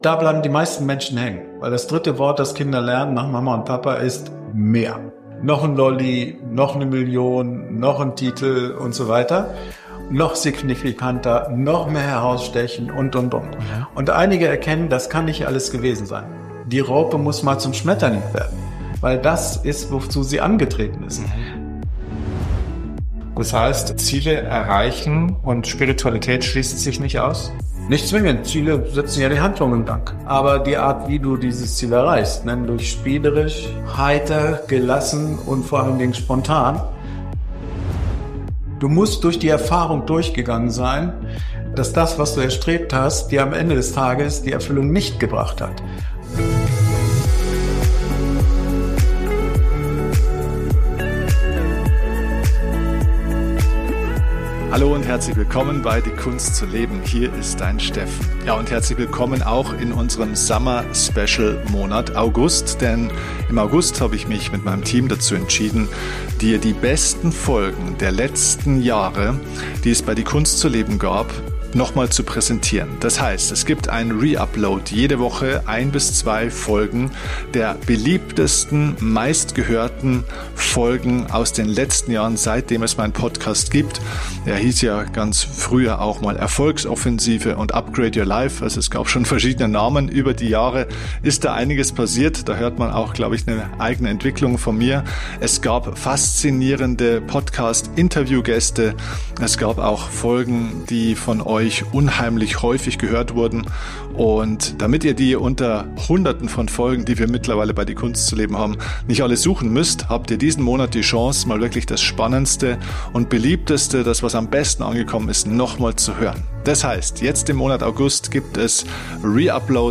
1 Season 2 Folge #26 - Wie komme ich in die Presse? Interview